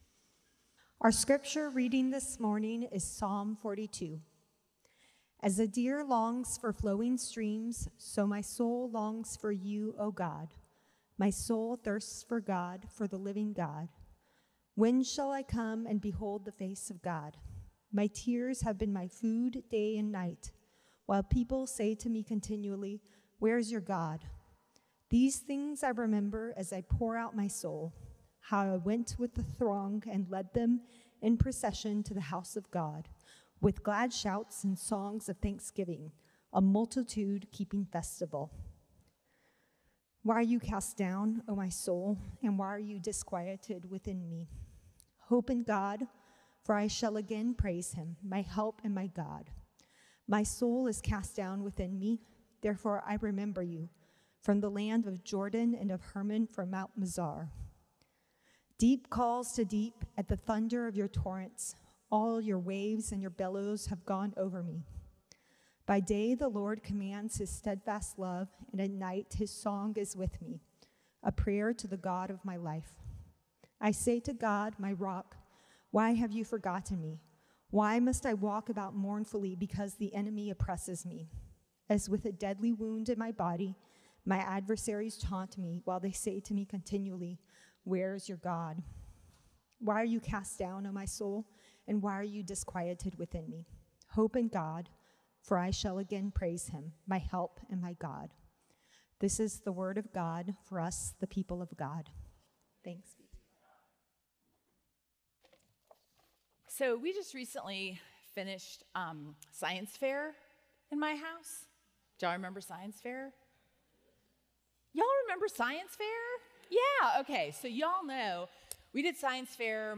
The Bible’s Prayerbook” Sermon Series